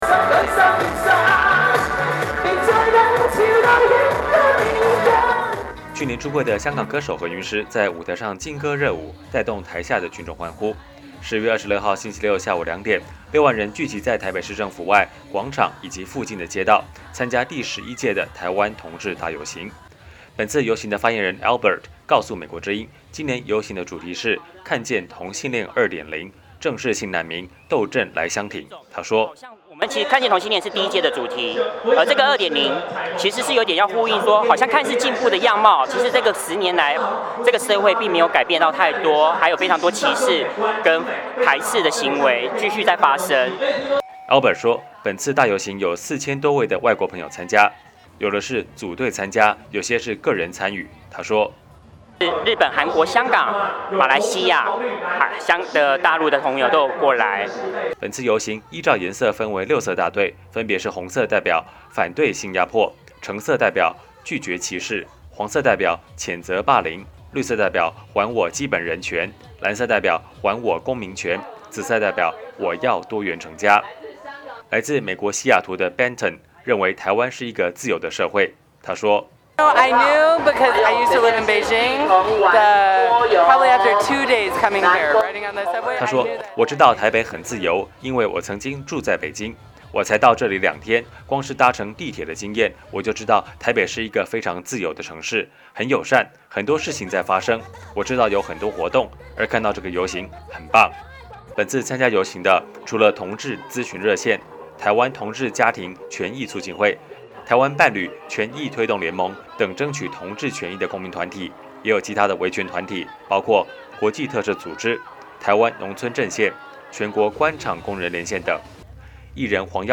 去年出柜的香港歌手何韵诗在舞台上劲歌热舞，带动台下群众欢呼。